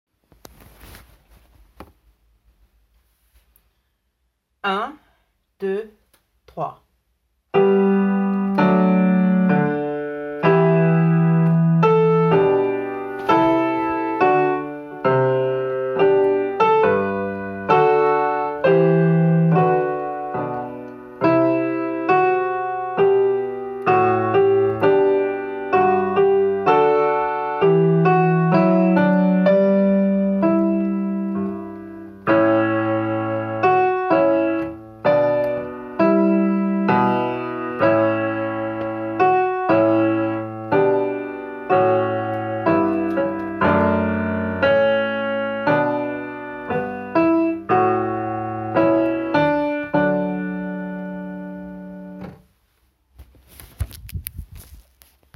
P23 haendel menuet acc piano
p23-haendel-menuet-acc-piano.mp3